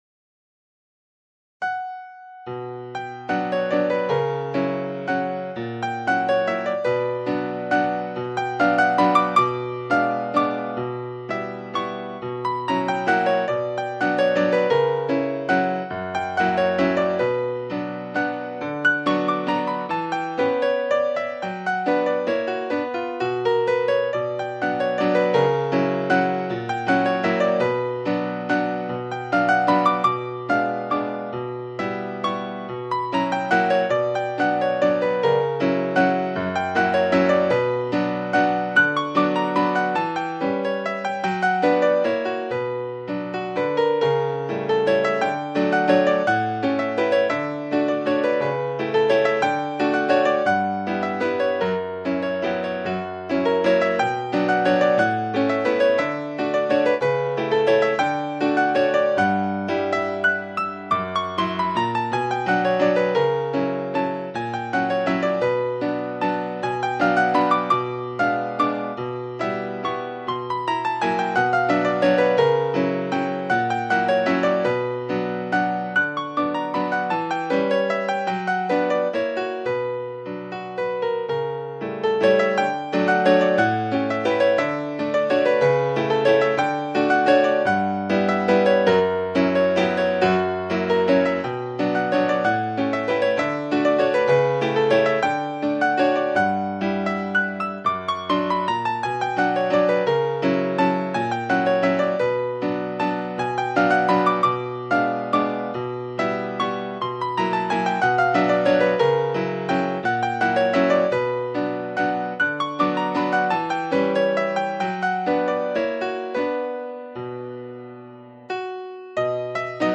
スラブ的な憂い